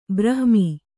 ♪ brahmi